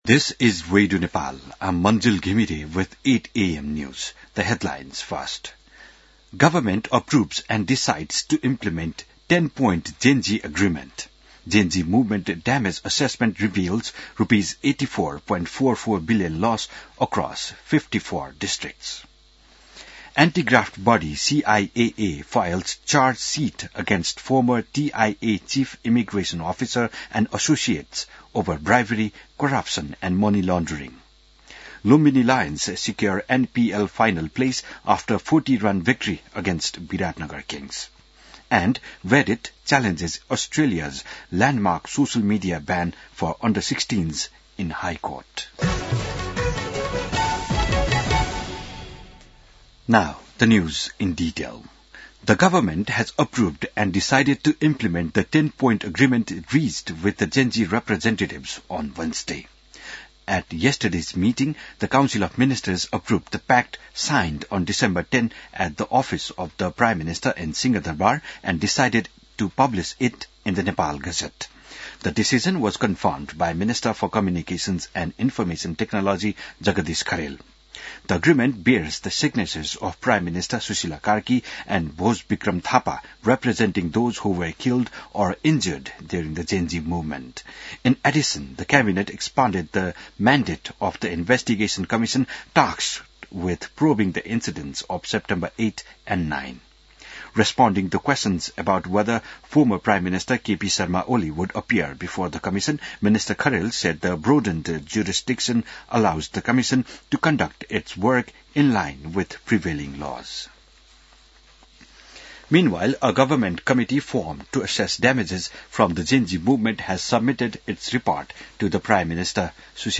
बिहान ८ बजेको अङ्ग्रेजी समाचार : २६ मंसिर , २०८२